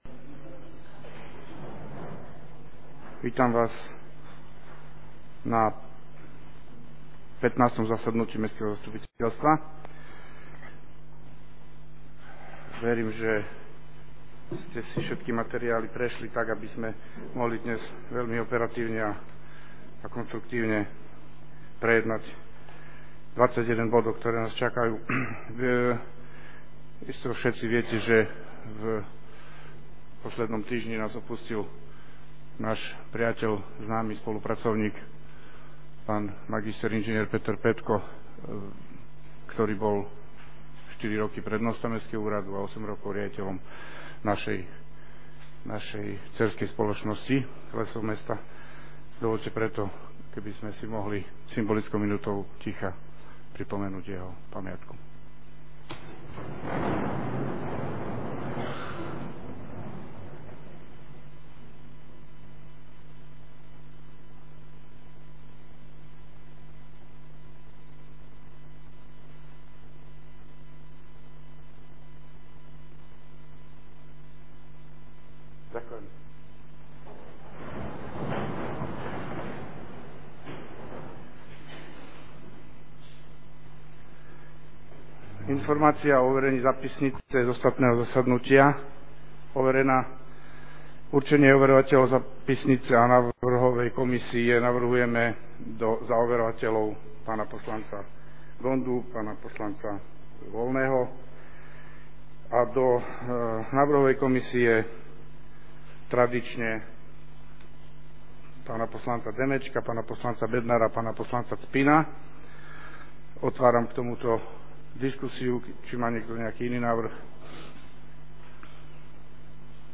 Zvukový záznam zo zasadnutia mestského zastupiteľstva